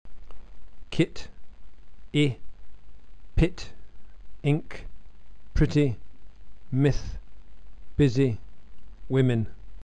Lax vowels are always short